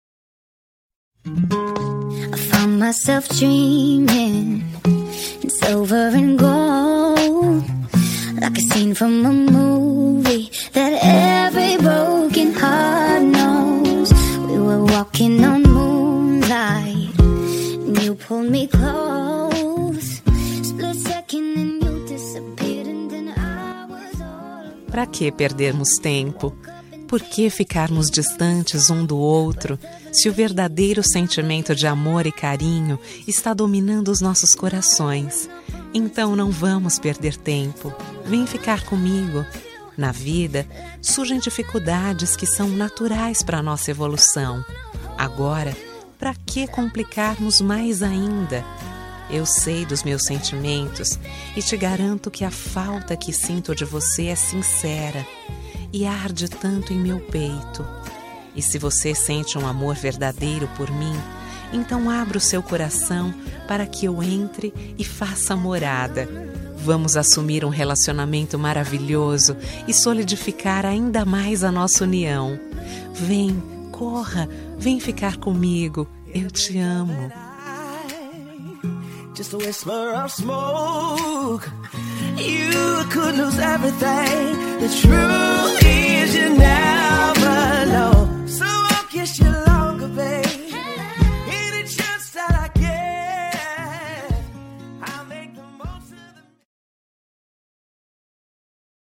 Telemensagem Paquera – Voz Feminina – Cód: 051587